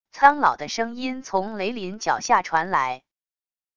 苍老的声音从雷林脚下传来wav音频生成系统WAV Audio Player